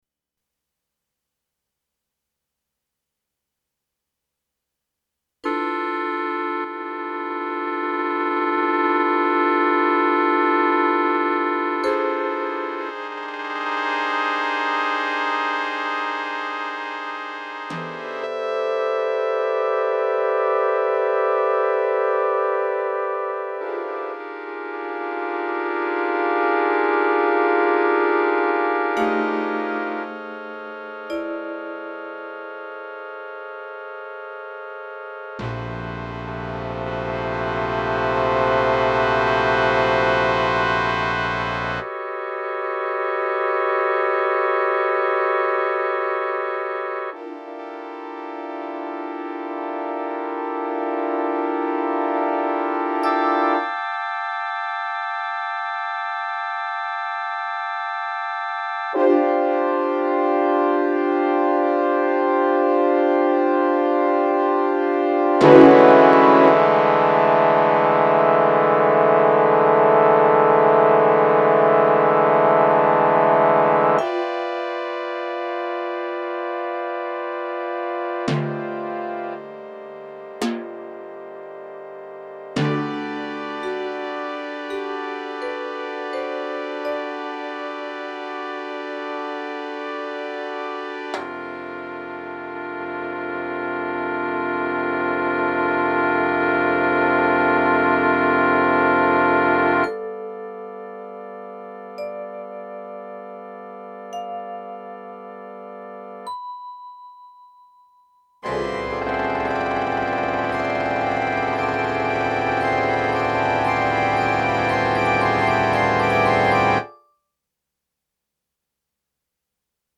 Genre: Band
Percussion 3 (vibraphone, crotales, bells)
Percussion 4 (marimba, medium tenor drum)